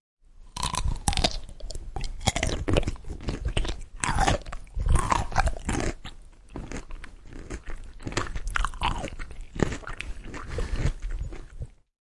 福里雪的脚步声
描述：用玉米粉制作的福来脚步声
Tag: 雪地 脚步声 拟音